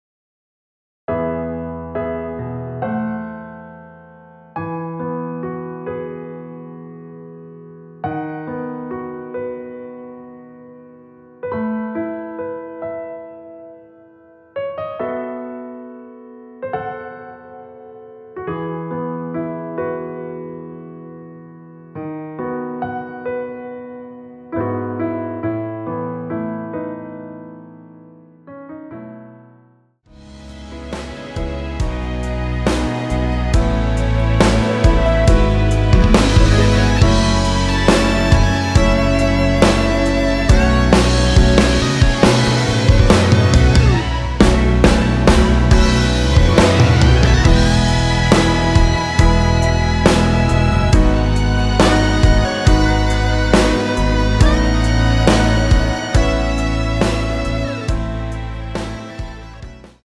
노래 들어가기 쉽게 전주 1마디 만들어 놓았습니다.(미리듣기 확인)
앞부분30초, 뒷부분30초씩 편집해서 올려 드리고 있습니다.